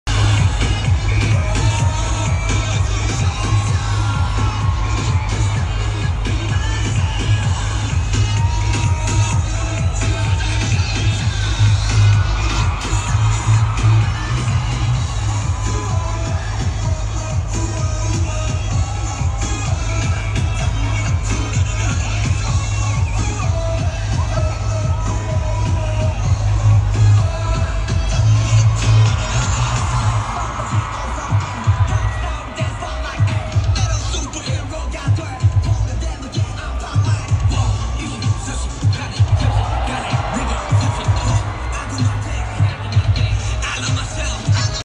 V from BTS is getting very loud cheers as he takes the field to warm up for his first pitch.